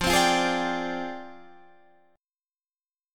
Fm6add9 Chord
Listen to Fm6add9 strummed